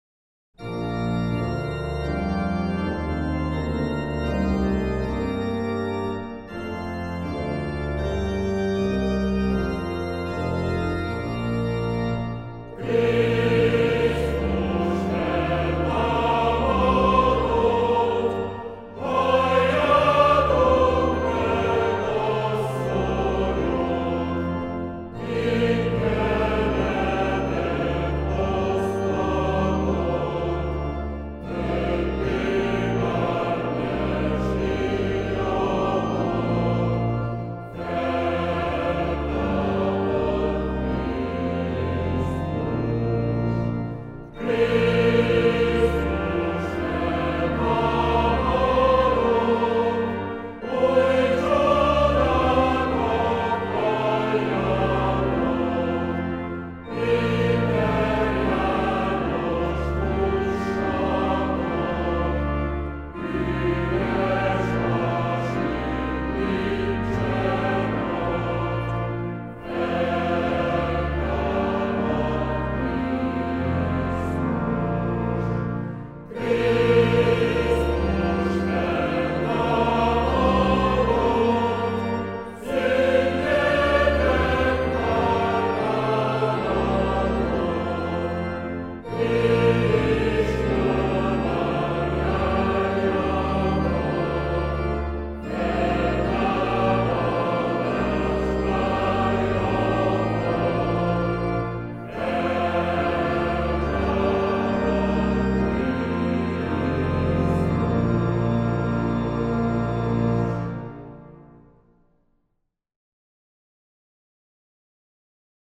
Magyar-Állami-Operaház-Kórusa-Krisztus-feltámadott.mp3